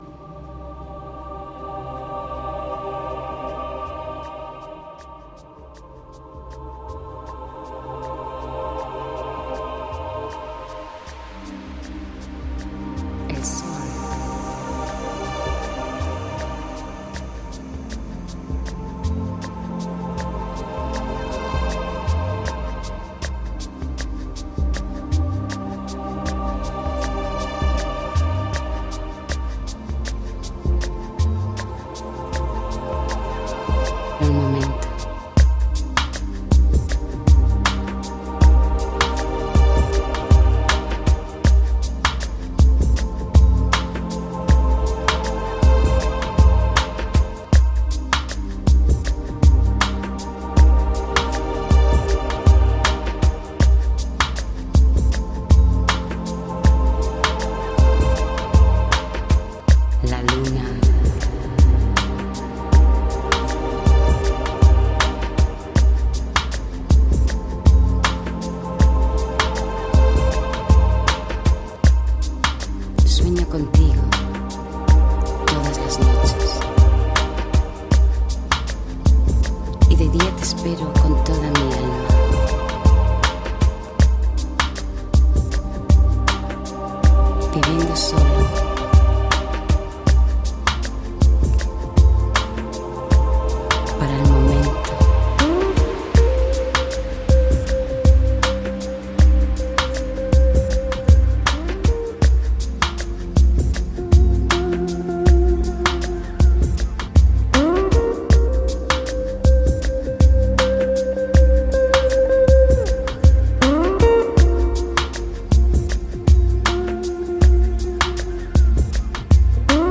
音乐类型：New Age / Meditation
偶尔出现的女声，无论是演唱还是吟诵，都恰倒好处。
迷幻电子音乐，穿插其间的女高音，动人心魄。